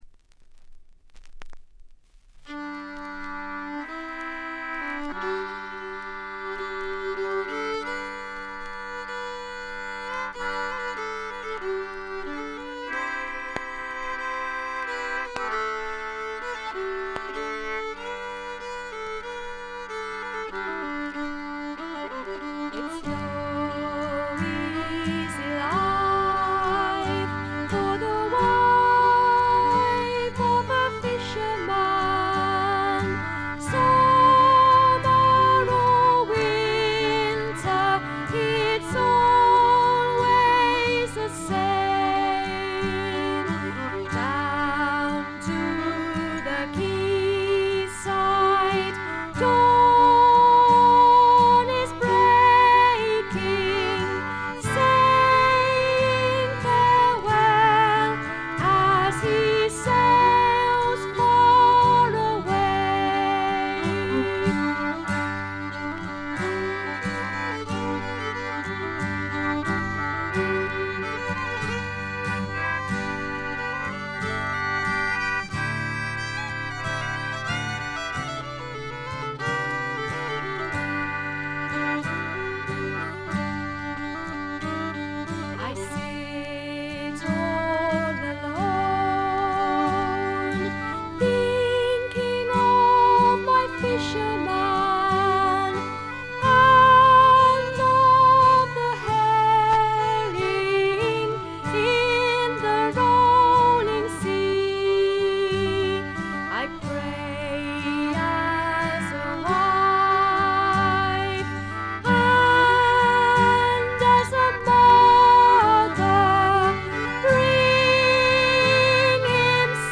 バックグラウンドノイズ、チリプチ多め大きめ。プツ音少々。
すべて自作の曲をまるでトラッドのように演奏しています。
試聴曲は現品からの取り込み音源です。